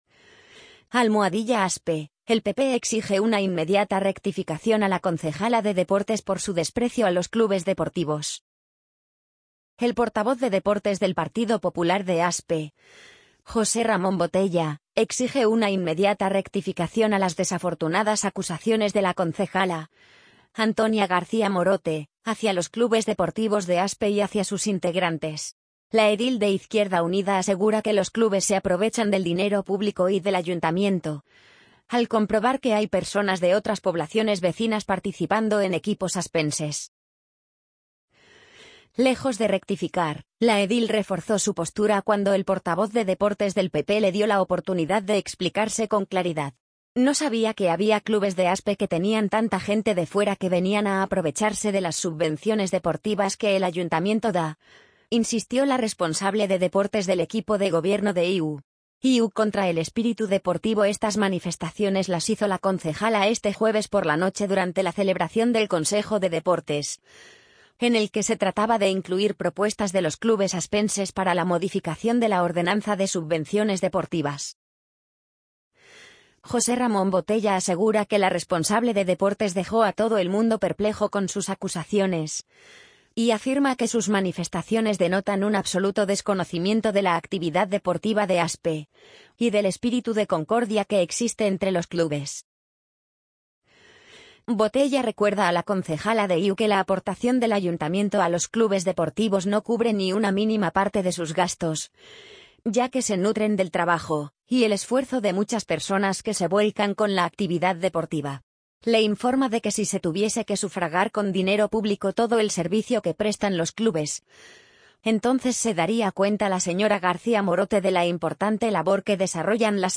amazon_polly_50702.mp3